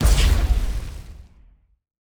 weapon_flame_006.wav